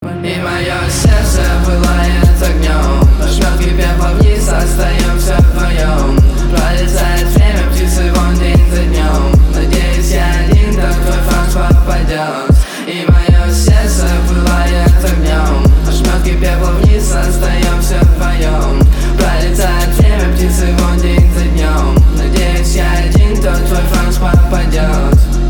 • Качество: 320, Stereo
мужской вокал
атмосферные
Trap
басы